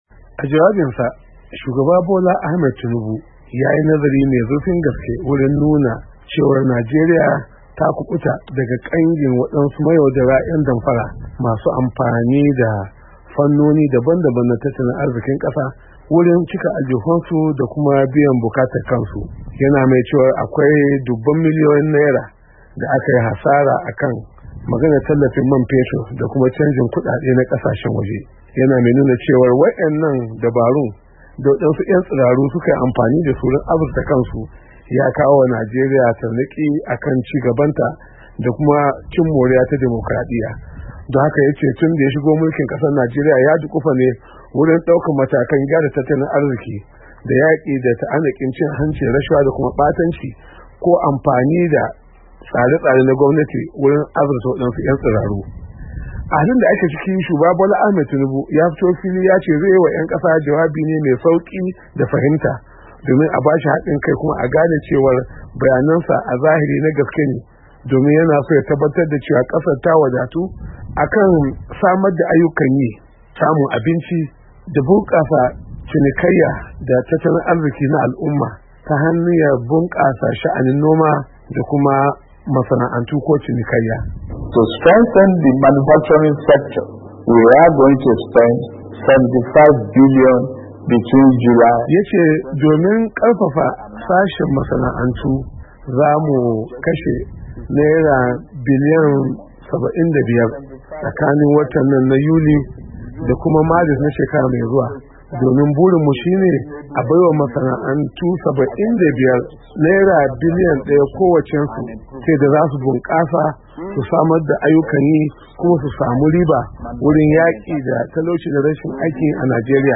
Jawabin Shugaban Najeriya Kan Yanayin Matsin Tattalin Arziki Da Kasar Take Ciki